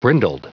Prononciation du mot brindled en anglais (fichier audio)
Prononciation du mot : brindled